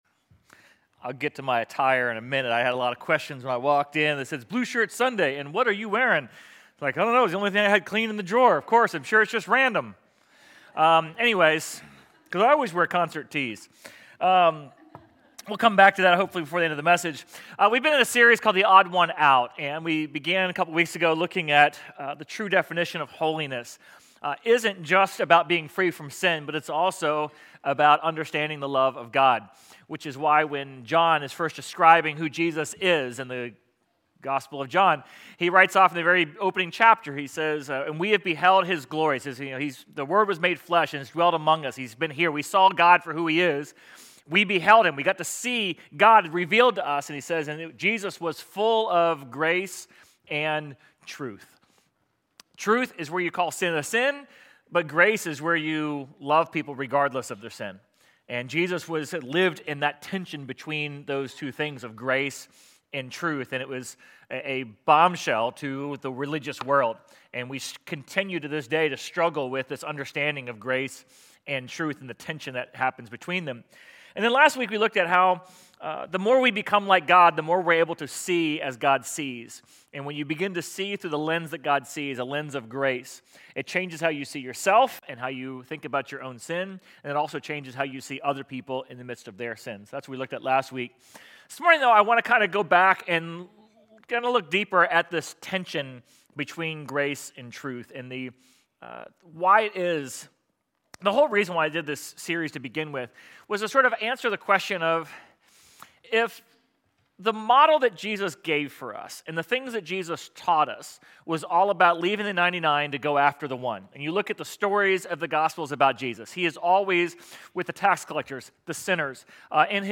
Sermon_10.5.25.mp3